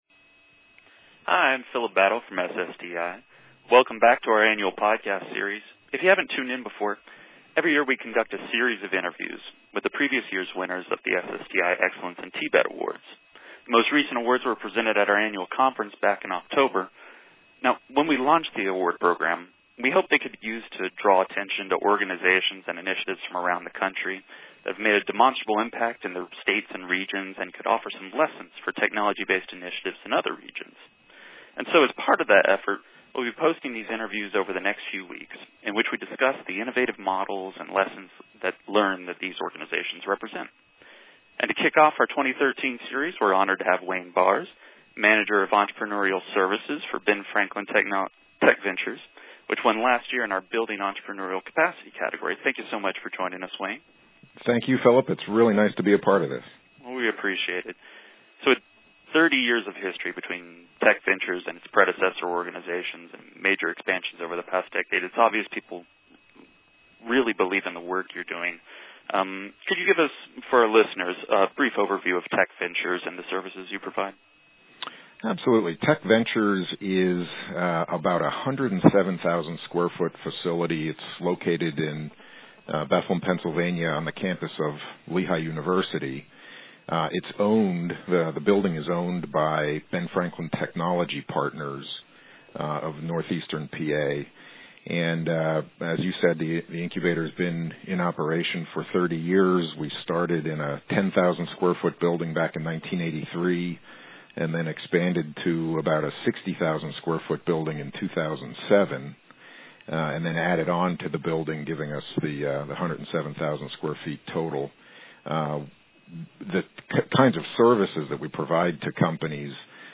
Listen to SSTI's Interview